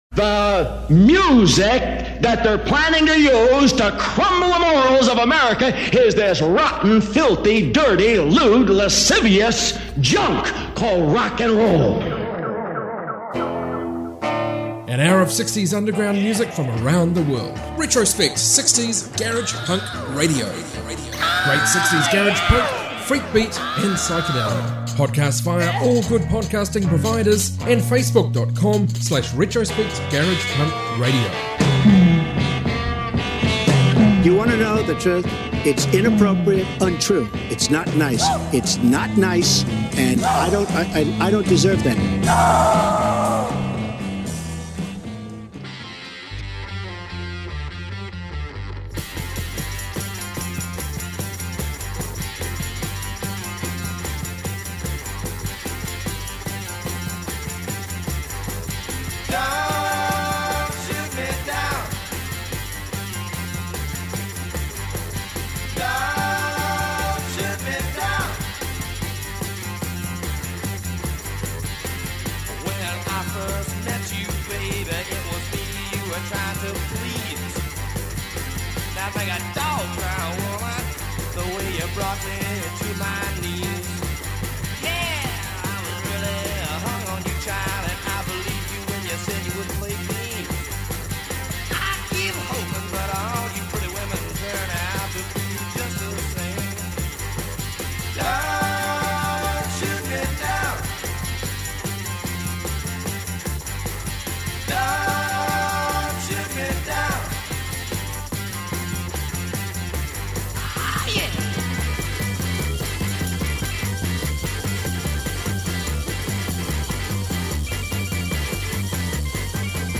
60s garage